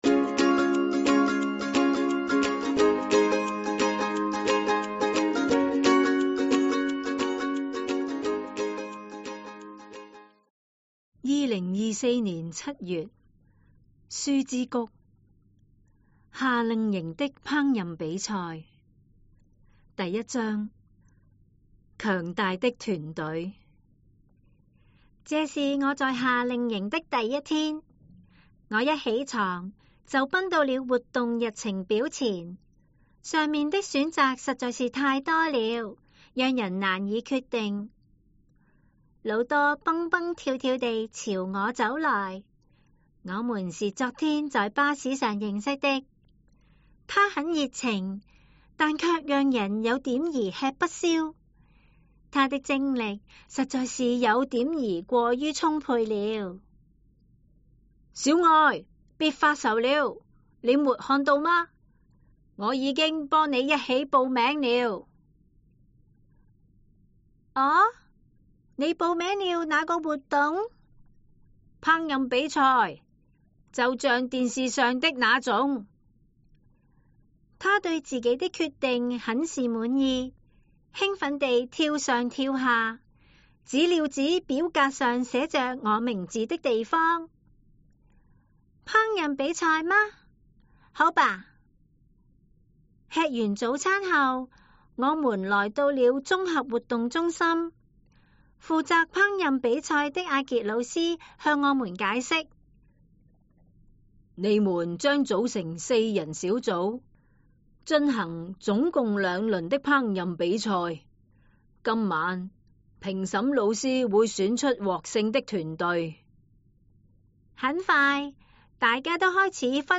The recording by professional actors brings the stories to life while helping with the comprehension and the pronunciation.